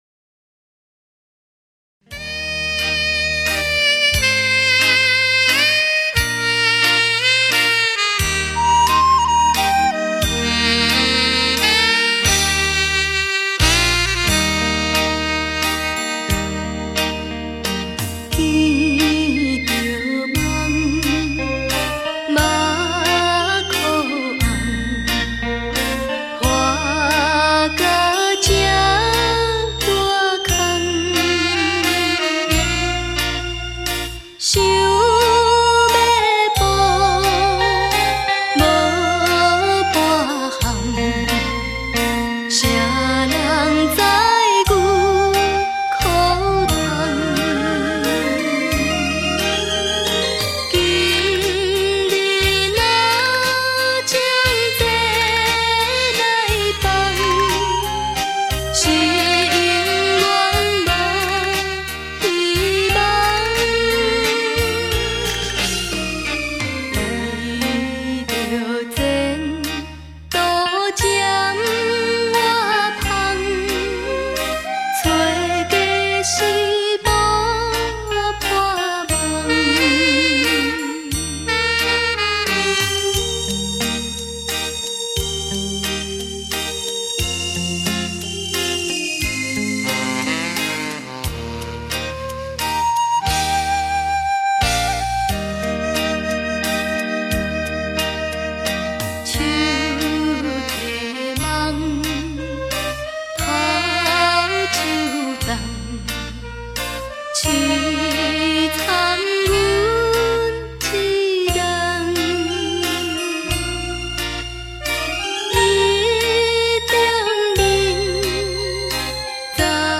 台语CD